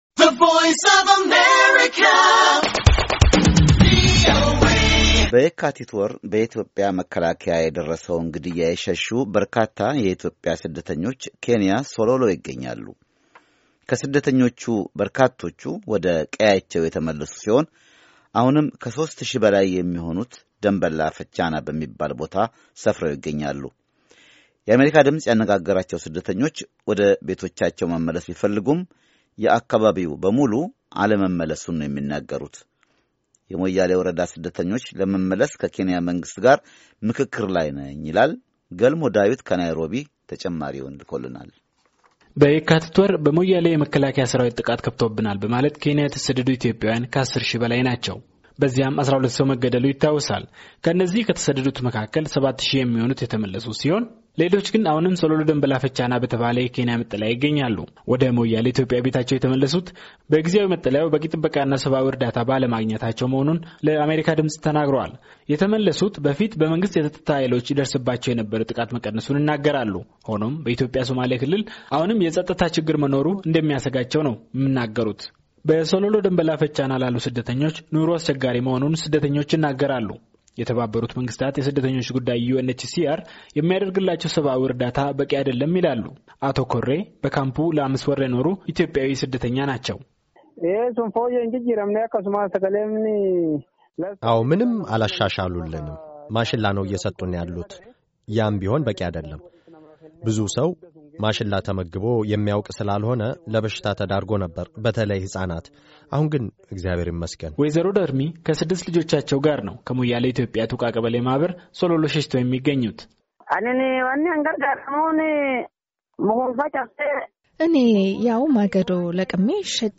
ዜና
የአሜርካ ድምፅ ያነጋገራቸው ስደተኞች ወደ ቤታቸው መመለስ ቢፈልጉም የአካባቢው ሙሉ በሙሉ አለመመለሱን ነው የሚናገሩት። የሞያሌ ወረዳ ስደተኞቹ ለመመለስ ከኬንያ መንግሥት ጋር ምክክር ላይ ነኝ ይላል፡፡